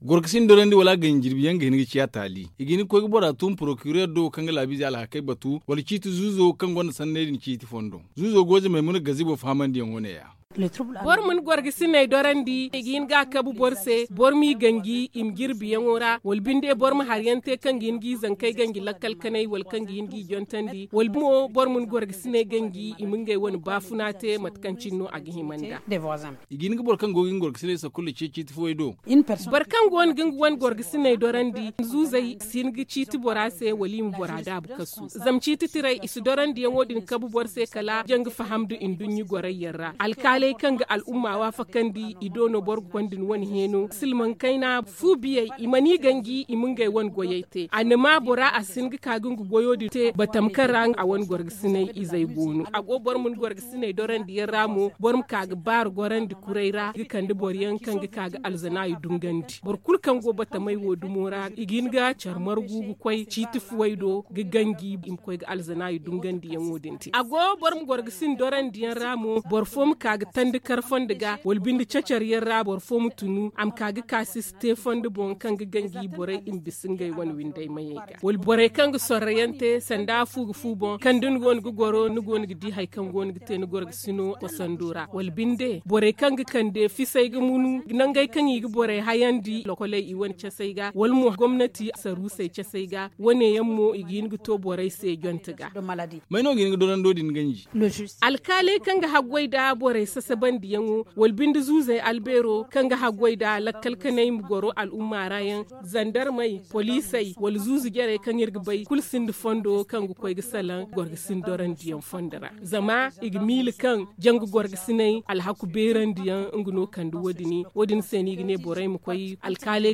la magistrate Gogé Maimouna Gazibo interrogée